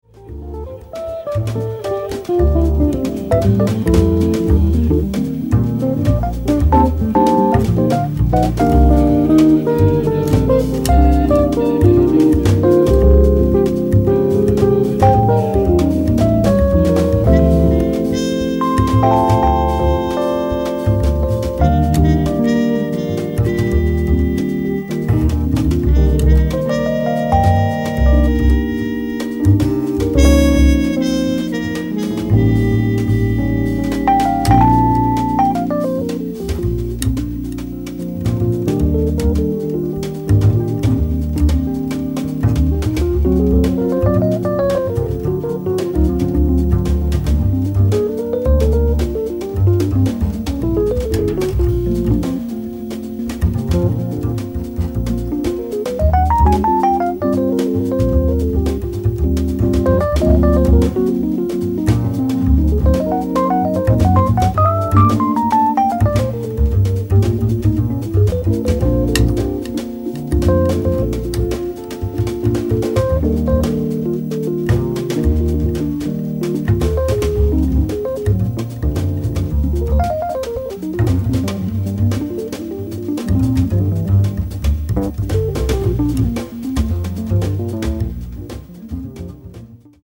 guitar
Fender Rhodes
bass
drums
trumpet, flugelhorn